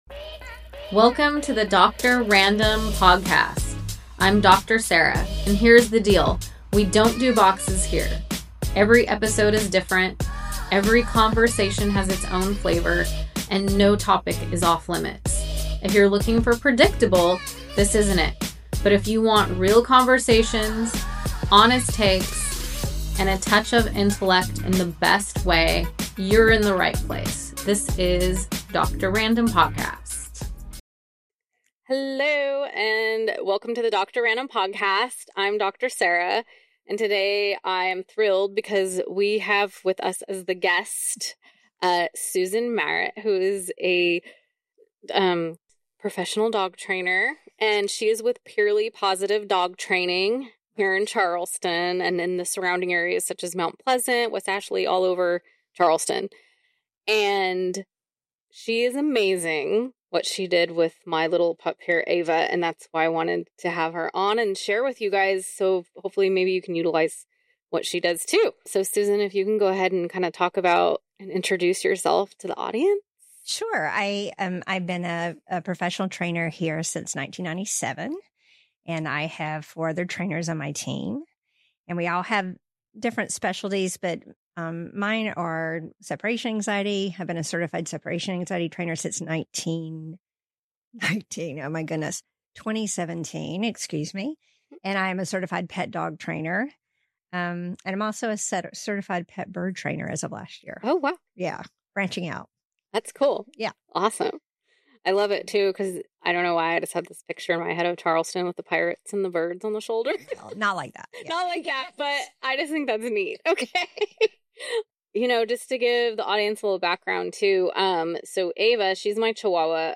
A warm, insightful conversation for every dog lover.